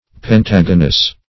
Pentagonous \Pen*tag"o*nous\, a.